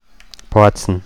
Deutsch-mundartliche Form
[pɔatsn]
Bozen_Mundart.mp3